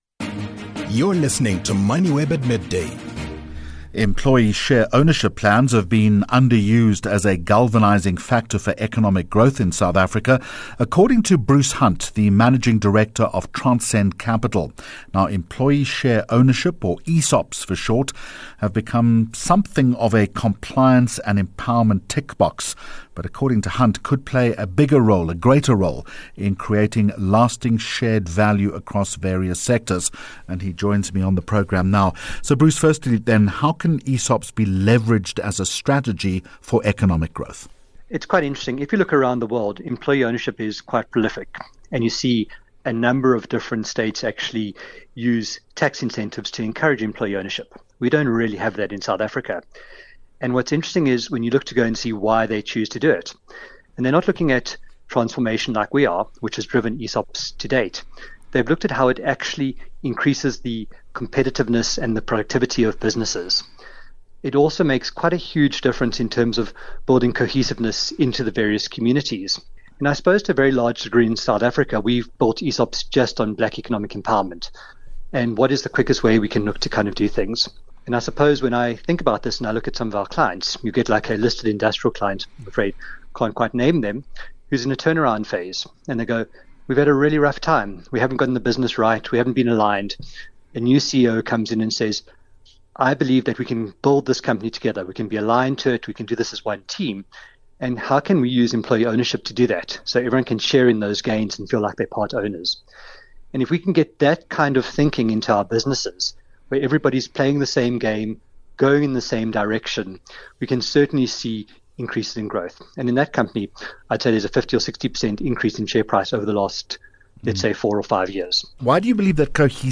Moneyweb interview – ESOPs as facilitators of economic growth
Moneywebmidday interview with Jeremy Maggs (JM)- Employee share ownership plans have been underused as a galvanising factor for economic growth in South Africa